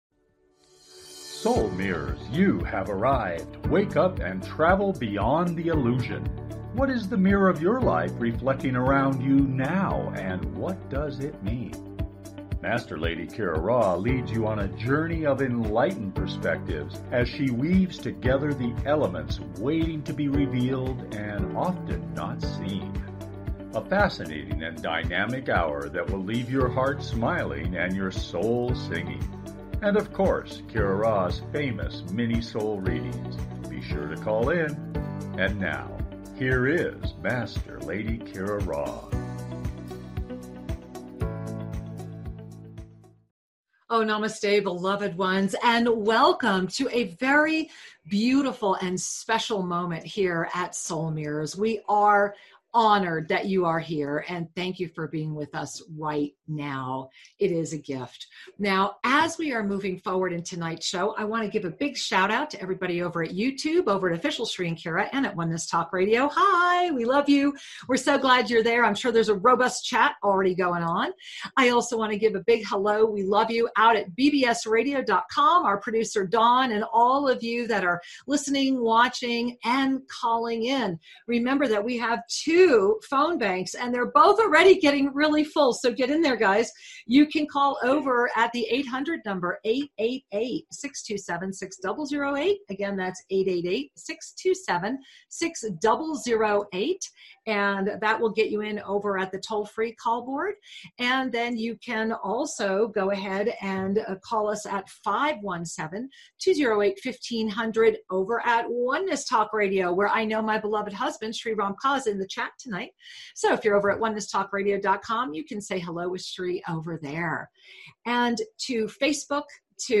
Talk Show
Lively, entertaining, and refreshingly authentic, the hour goes quickly!